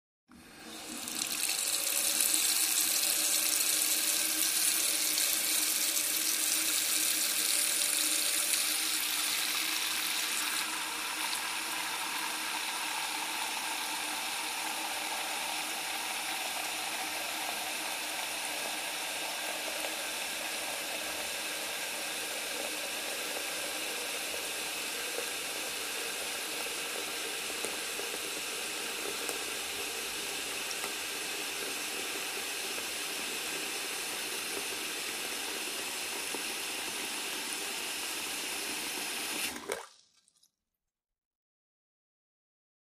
Sink Water
fo_sink_rundrainclos_01_hpx
Bathroom sink water runs with drain open and closed.